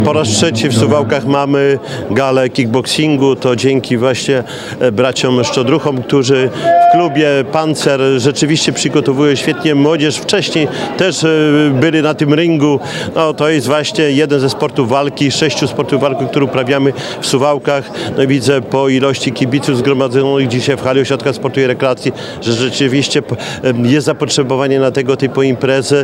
– Suwalczanie bardzo lubią sporty walki, co widać po licznej widowni – powiedział Radiu 5 Czesław Renkiewicz, prezydent Suwałk.